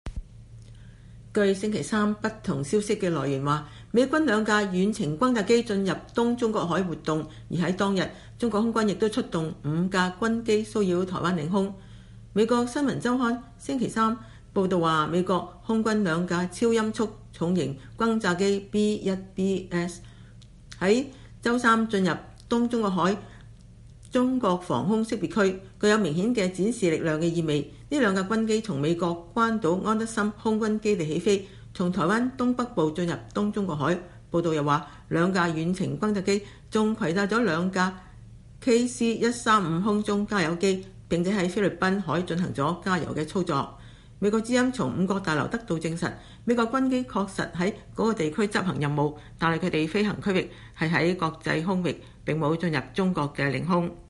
這個長約一分鐘的視頻裡有中國空軍飛機駕駛員與美國轟炸機人員的對話，可以聽到中國飛行員說“請立即離開”的聲音。